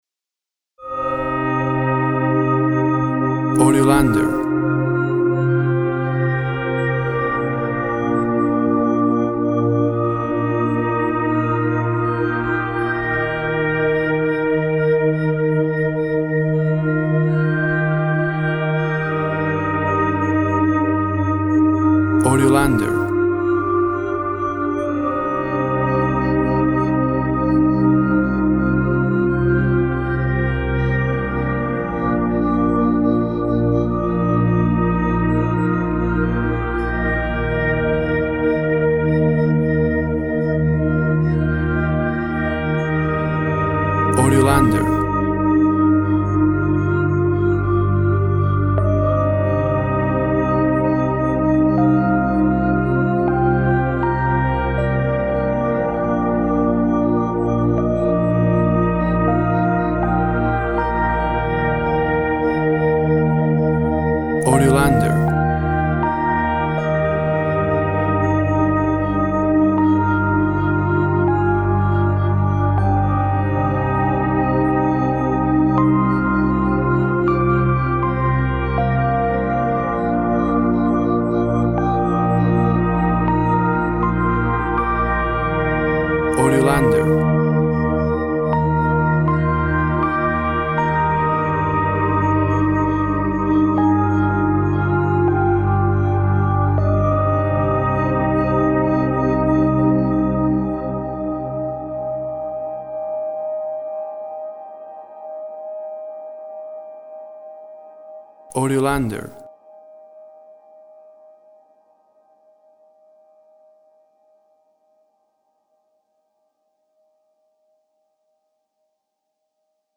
A sad, melancholic ambient and piano dominated song.
Tempo (BPM) 80